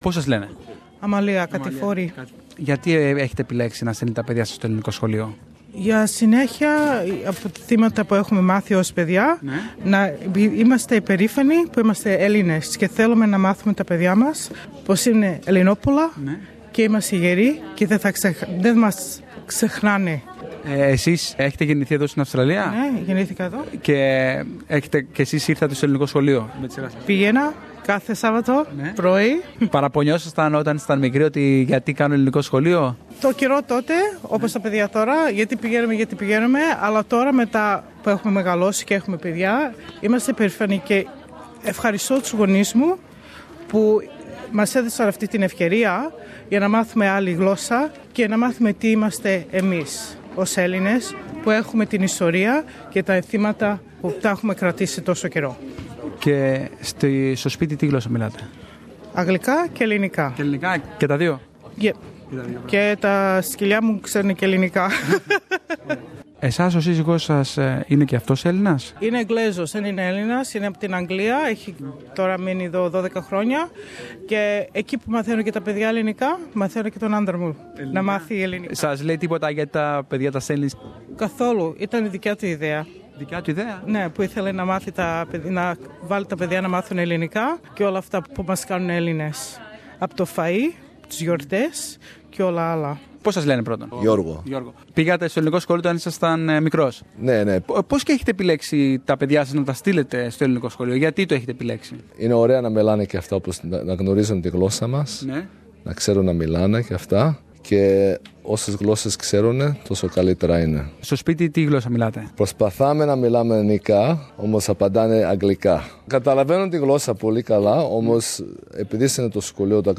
συνομίλησε με γονείς μαθητών για τη σημασία εκμάθησης της ελληνικής γλώσσας